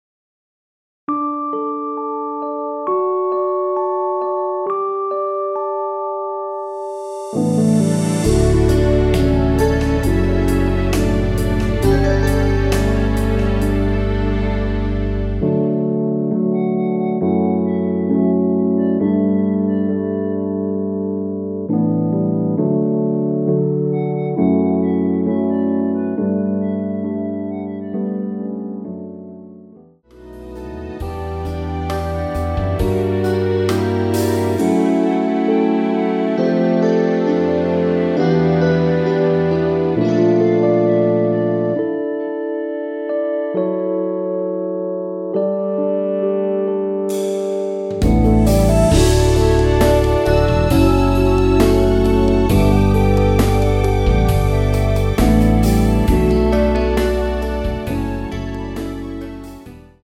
간주 2마디후 아래로 진행
멜로디 MR이란
노래방에서 노래를 부르실때 노래 부분에 가이드 멜로디가 따라 나와서
앞부분30초, 뒷부분30초씩 편집해서 올려 드리고 있습니다.
중간에 음이 끈어지고 다시 나오는 이유는